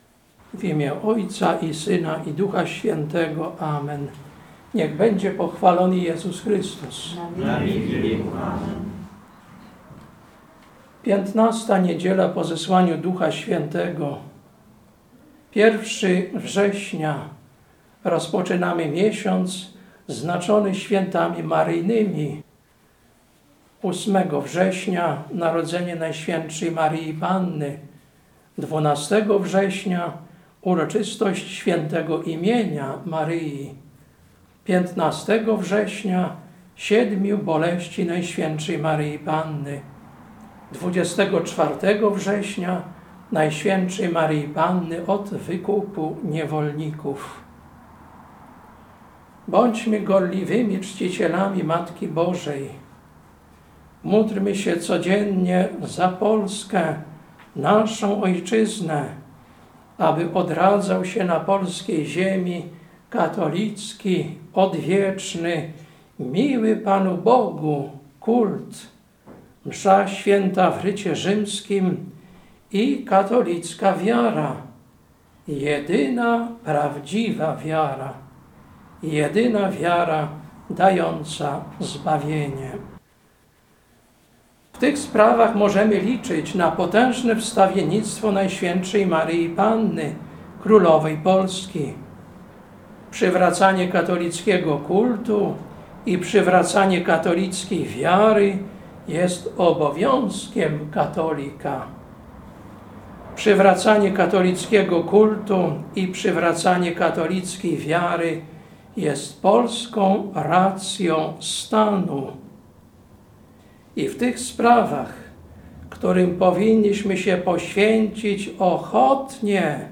Kazanie na XV Niedzielę po Zesłaniu Ducha Świętego, 01.09.2024